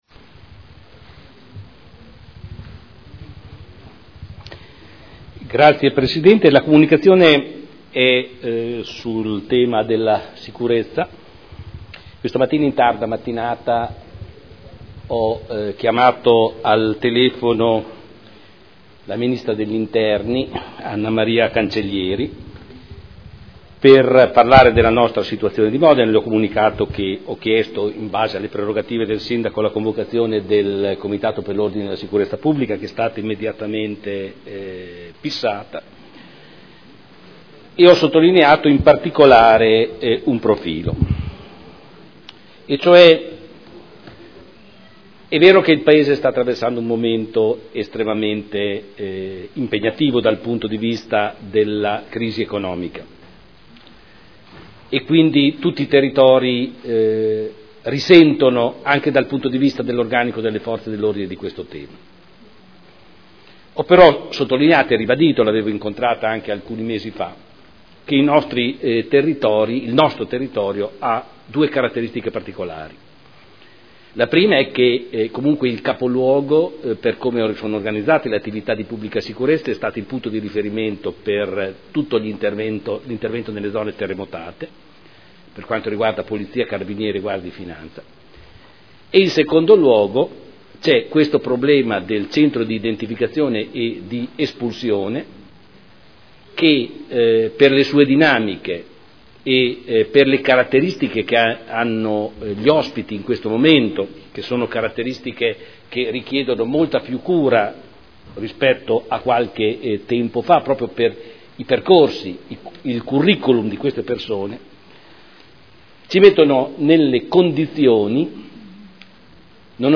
Comunicazione del Sindaco sulla sicurezza.